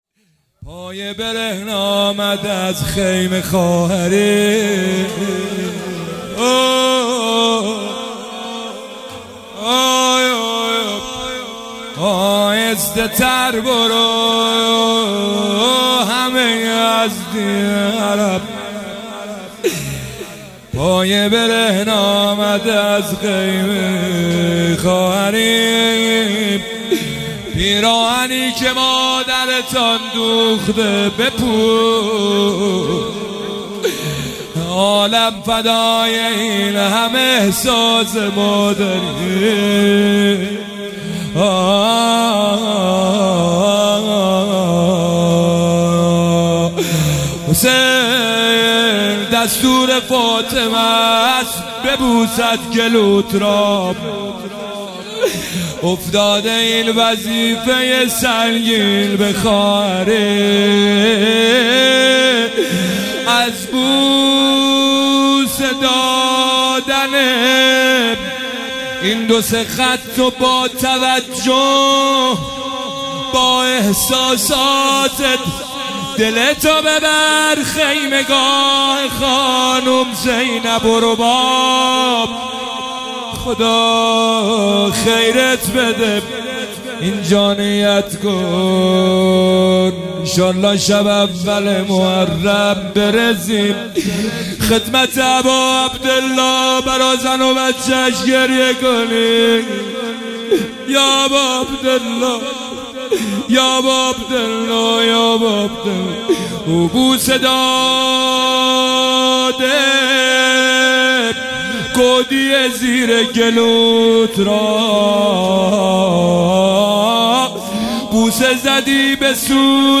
هیئت هفتگی 27 اردیبهشت 1404